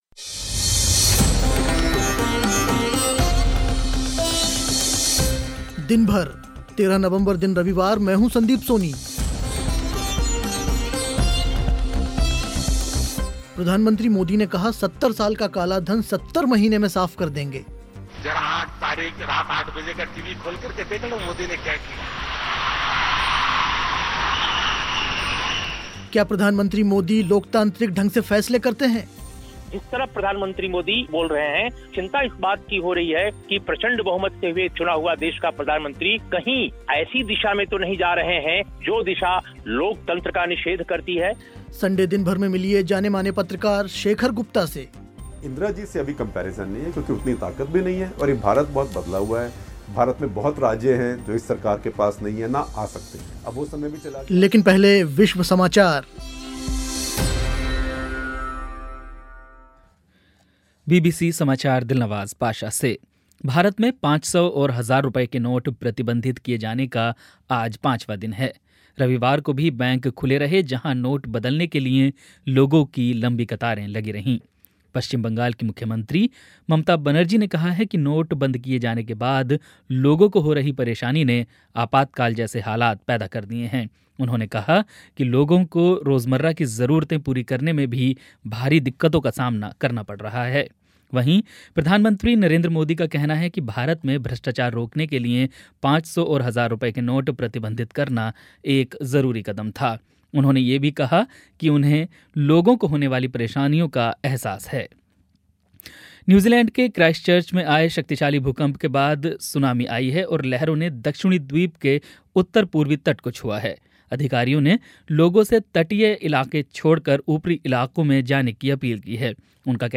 प्रधानमंत्री मोदी ने कहा 70 साल का काला धन 70 महीने में साफ़ कर देंगे. सुनिए क्या प्रधानमंत्री मोदी लोकतांत्रिक ढंग से फैसले करते हैं? संडे दिनभर में मिलिए जानेमाने पत्रकार शेखर गुप्ता से.